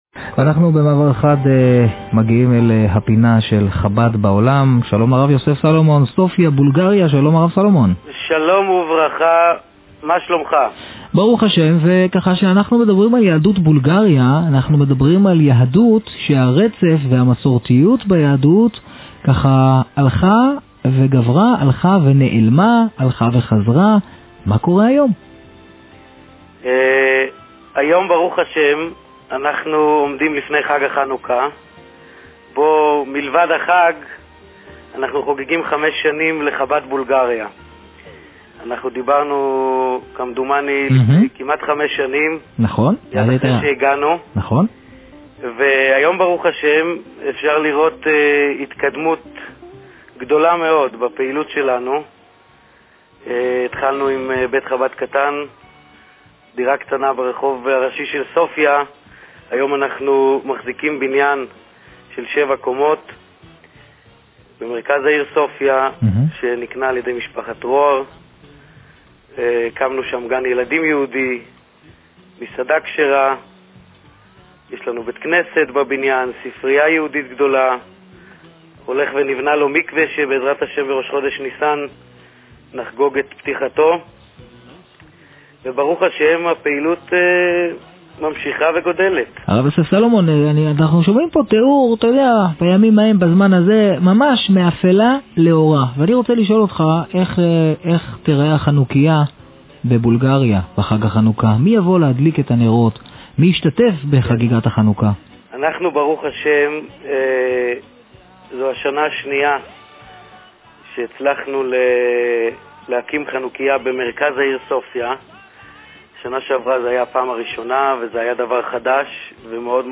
היה אורח הפינה הקבועה של ראיון עם שליח חב"ד